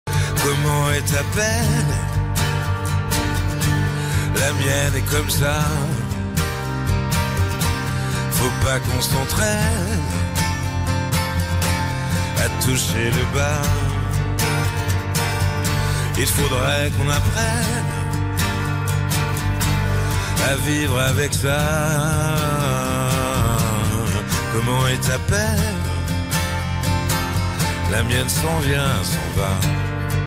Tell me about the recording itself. » en version live